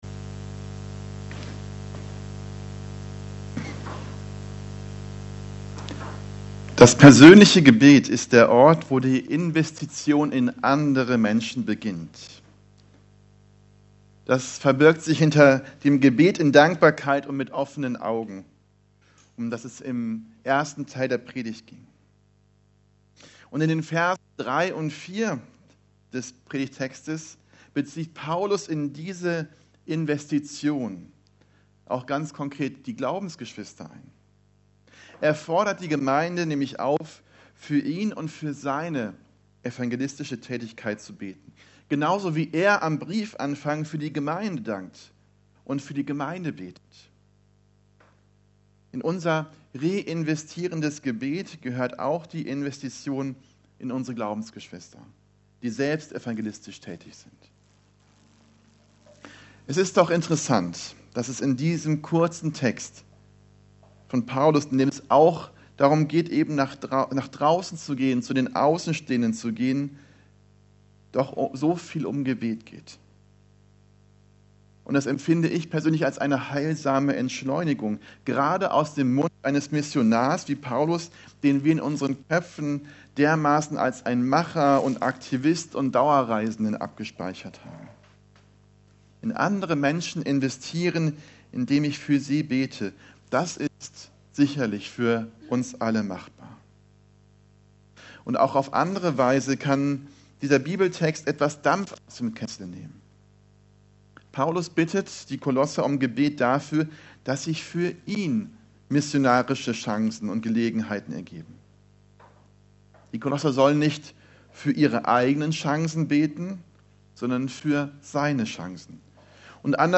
Evangelisch-Freikirchliche Gemeinde Kelkheim - Predigten anhören